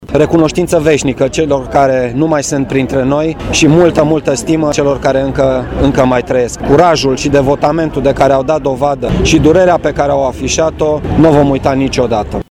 Dacă oficialitățile centrale nu au fost prezente la Brașov, manifestarea a fost onorată de numeroși oficiali brașoveni, printre care și prefectul Marian Rasaliu